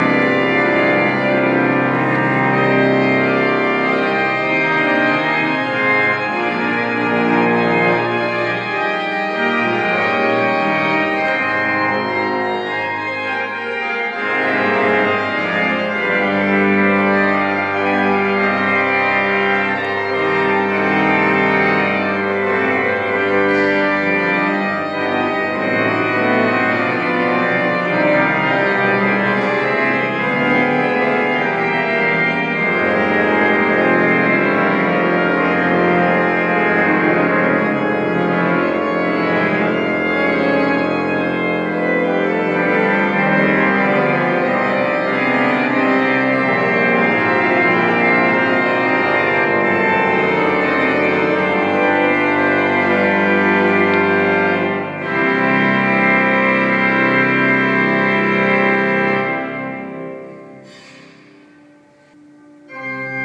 Musizierstunde an den Orgeln der Marktkirche Hannover
Musik